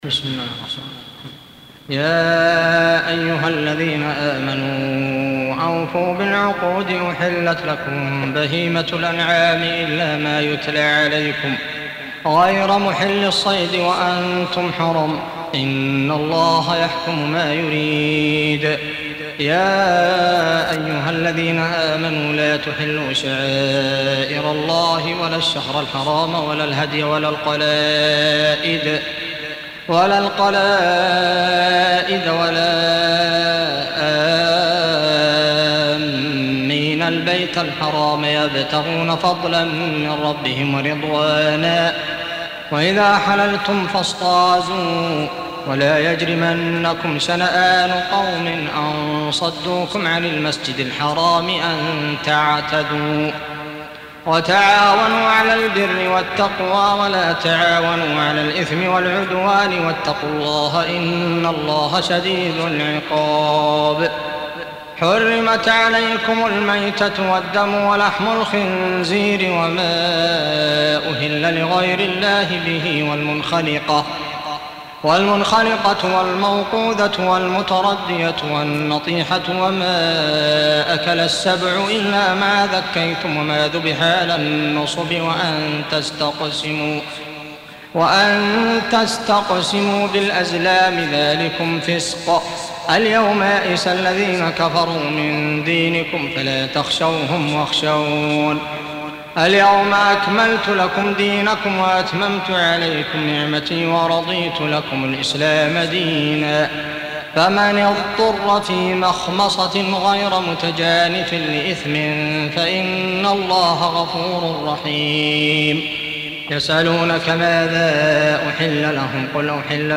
Surah Repeating تكرار السورة Download Surah حمّل السورة Reciting Murattalah Audio for 5. Surah Al-M�'idah سورة المائدة N.B *Surah Includes Al-Basmalah Reciters Sequents تتابع التلاوات Reciters Repeats تكرار التلاوات